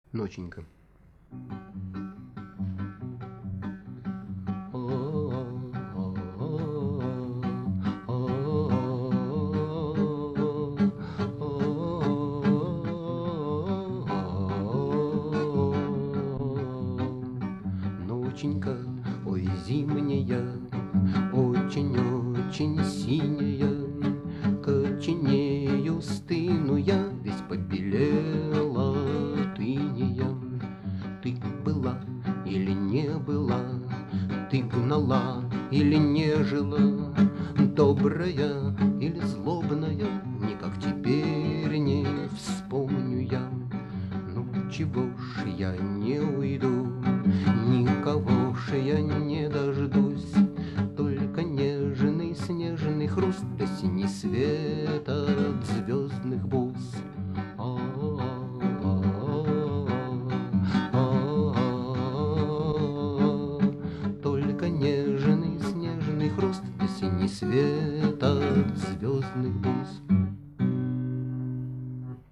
Исп. автор: